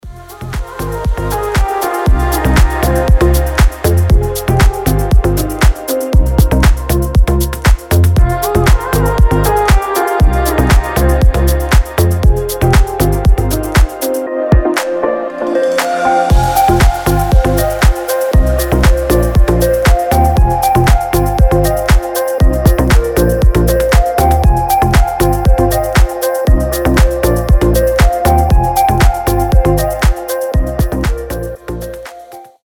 без слов
красивая мелодия
deep progressive
Блестящая мелодия для утра